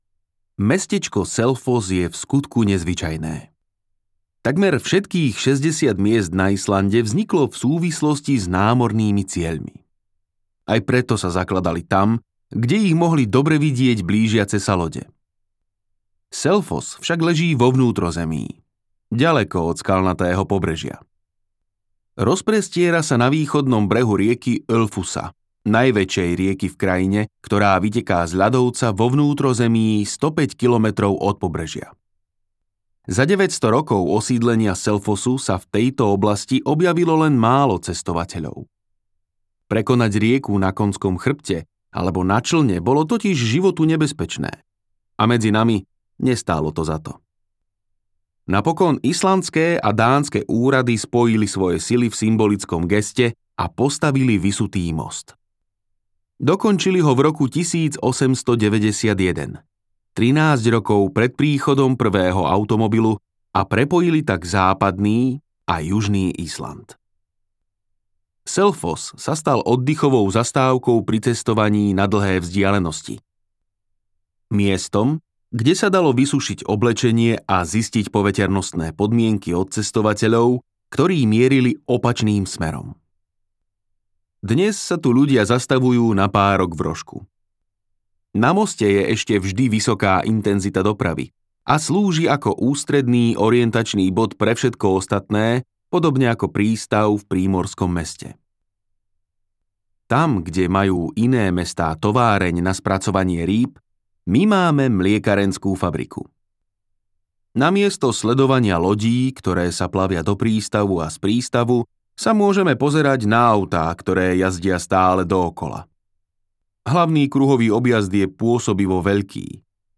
Ako Island zmenil svet audiokniha
Ukázka z knihy